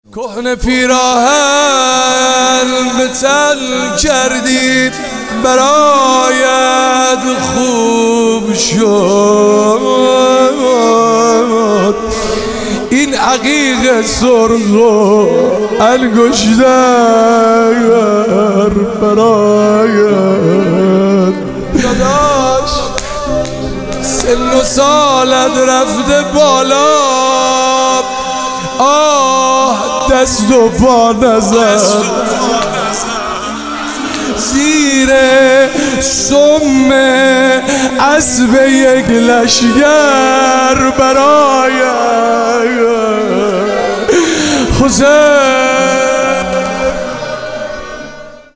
روضه امام حسین علیه السلام